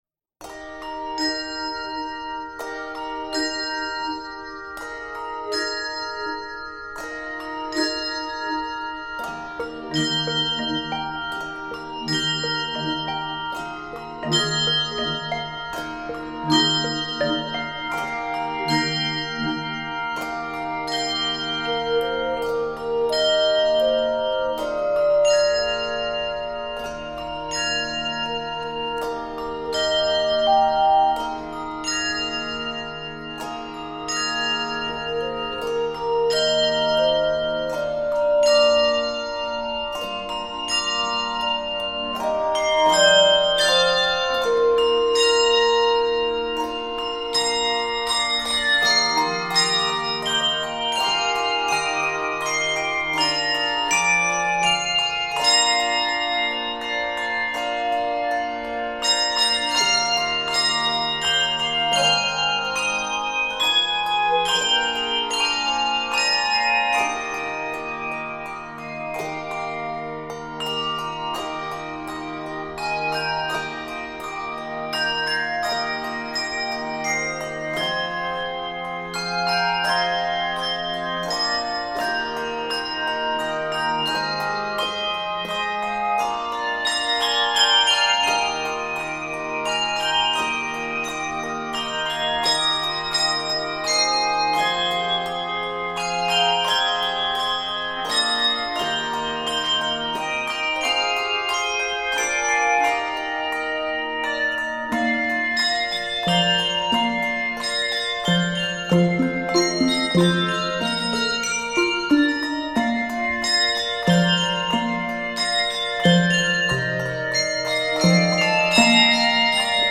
A variety of handbell techniques come together
Effective with or without the optional handchimes
Key of Eb Major.